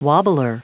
Prononciation du mot wobbler en anglais (fichier audio)
Prononciation du mot : wobbler
wobbler.wav